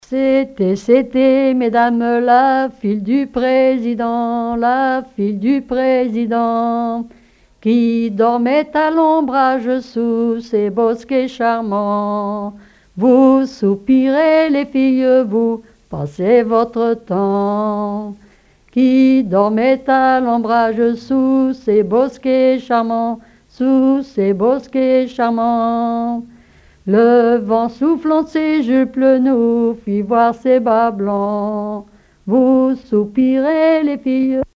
Genre laisse
collecte en Vendée
Témoignages et chansons traditionnelles